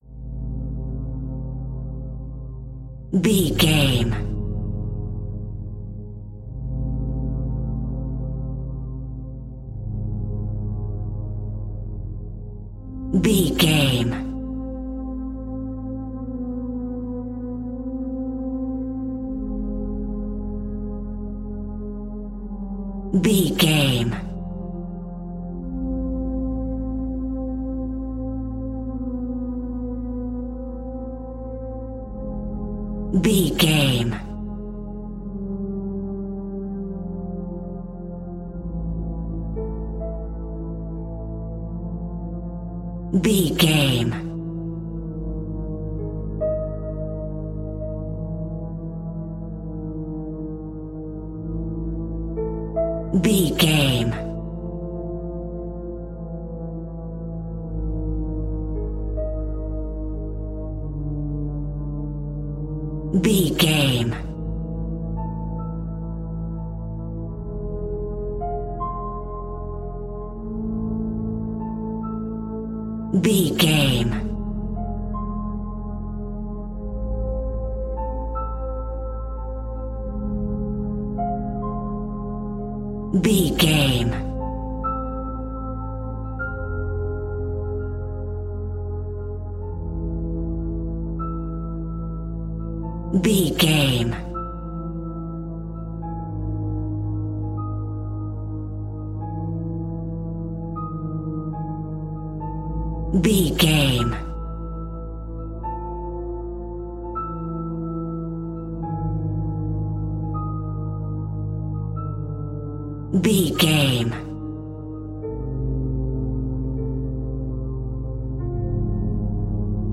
80s Scary Movie Music.
Aeolian/Minor
Slow
tension
ominous
eerie
strings
synthesiser
piano
Horror Pads
Horror Synths